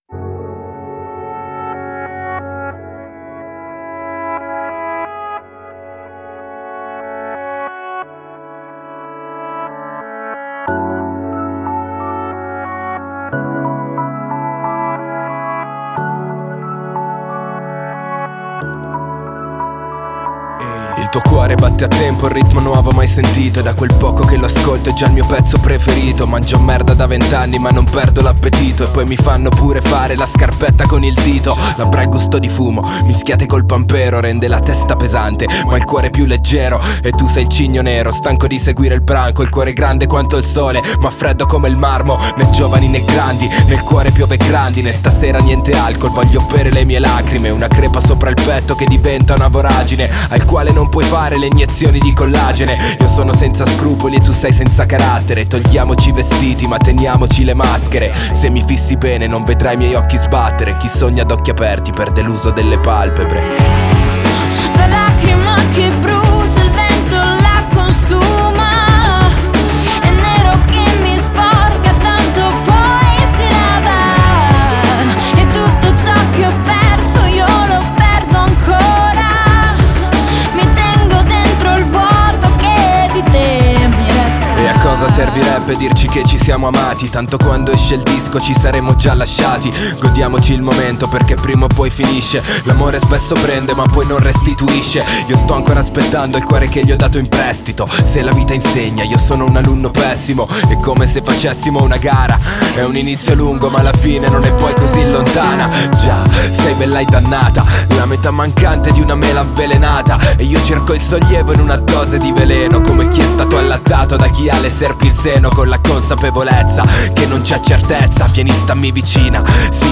Genre: Pop, Pop Rock